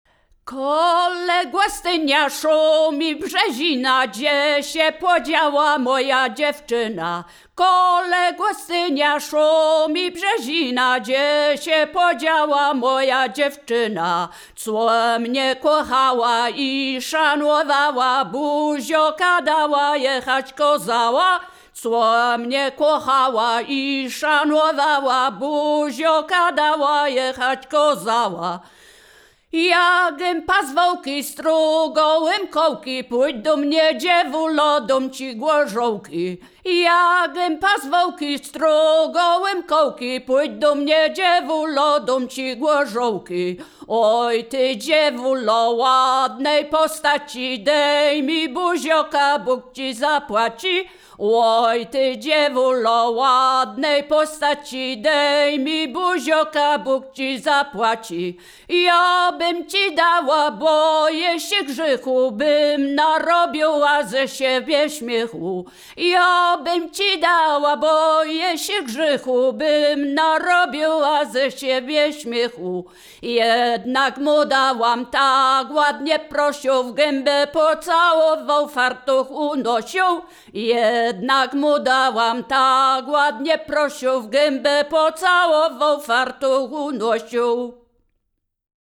województwo wielkopolskie, powiat gostyński, gmina Krobia, wieś Posadowo
liryczne miłosne